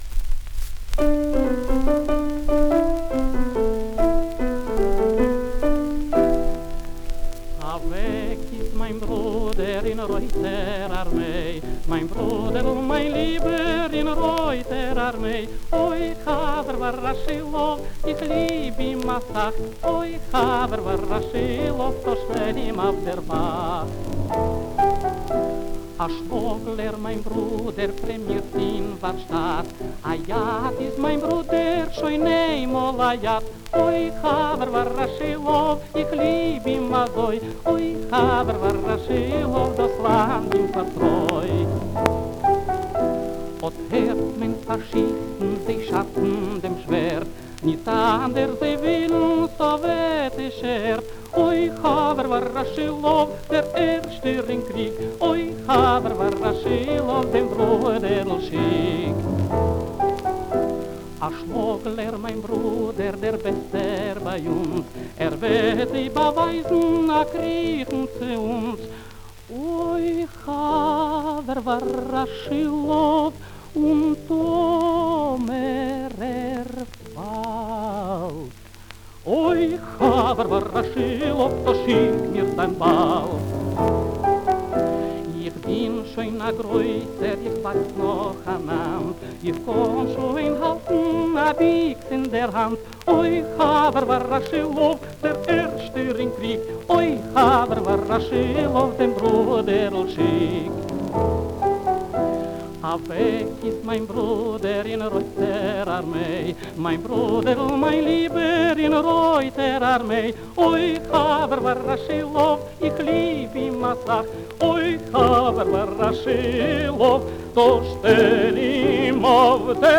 Лучше качество, чем запись уже на сайте.
ф-но